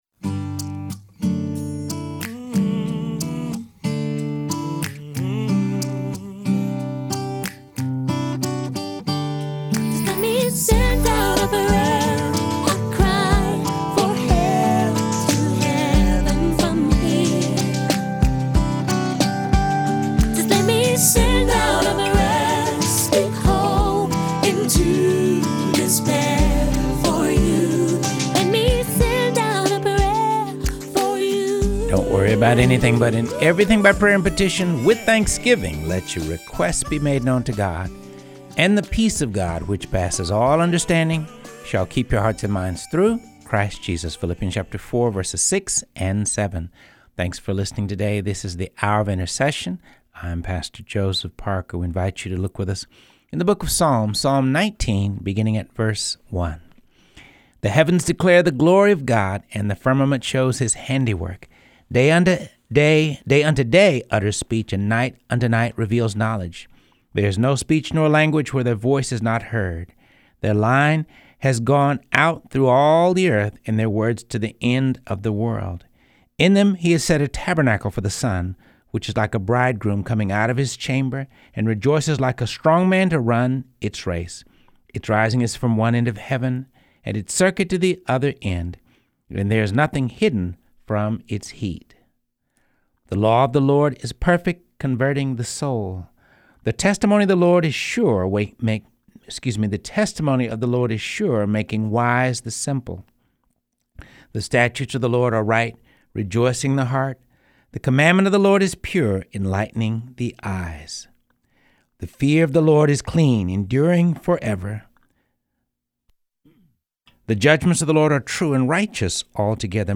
reading through the Bible